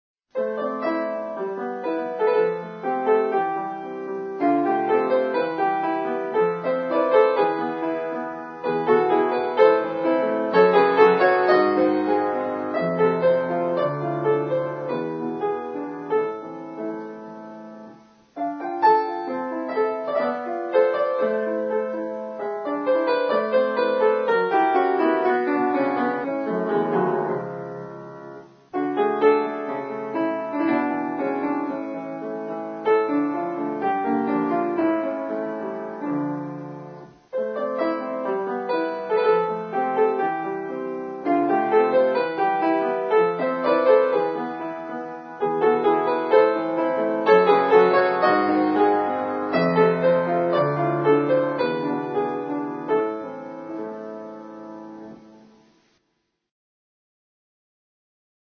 Piano Recital: